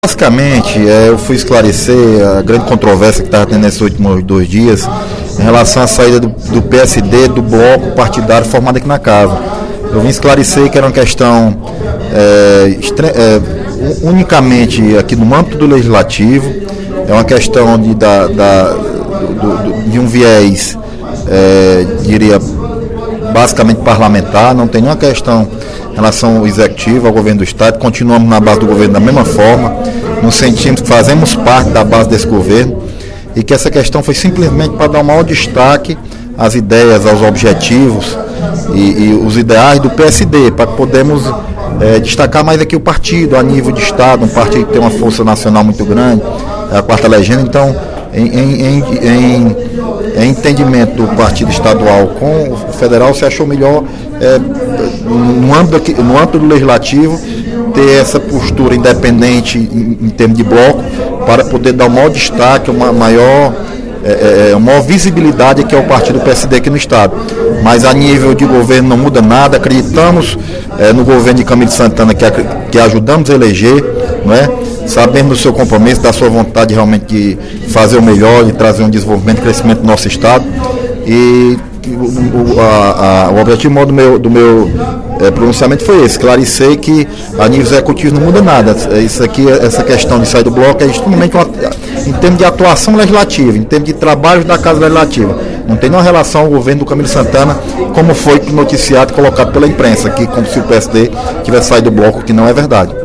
No primeiro expediente da sessão plenária desta quarta-feira (01/04), o deputado Leonardo Pinheiro (PSD) comunicou a decisão do seu partido de sair do bloco Pros/PT/PCdoB/PSD da Assembleia Legislativa.